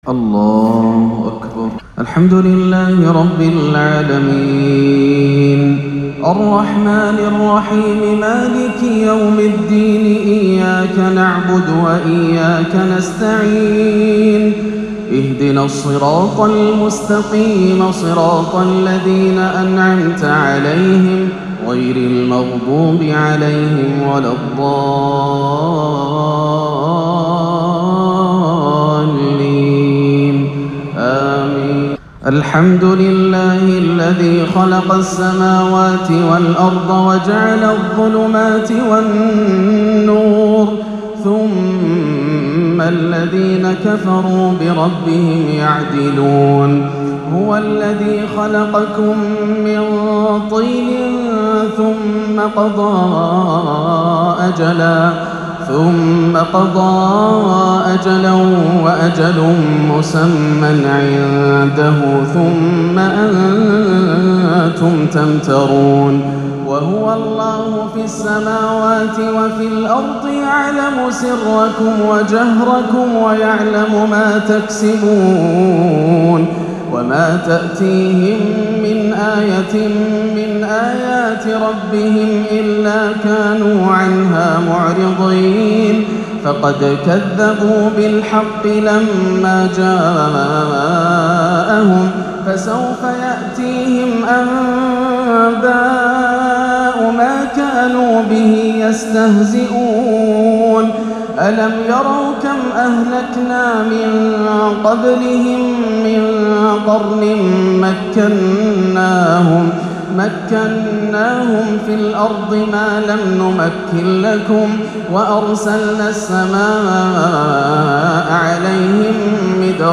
(قُل إِنّي أَخافُ إِن عَصَيتُ رَبّي عَذابَ يَومٍ عَظيمٍ) تلاوة بااكية فاقت الوصف لفواتح سورة الأنعام - عشاء الأربعاء 16-8 > عام 1439 > الفروض - تلاوات ياسر الدوسري